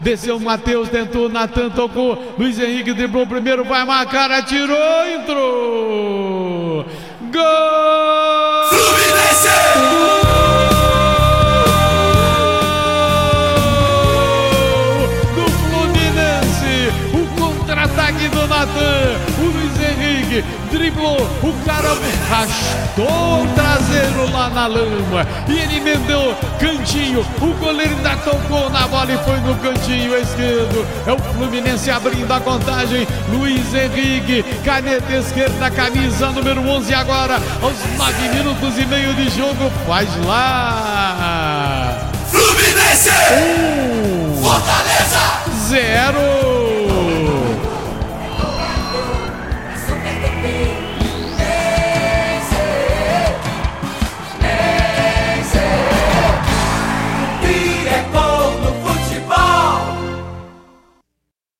Ouça o gol da vitória do Fluminense sobre o Fortaleza com a narração de José Carlos Araújo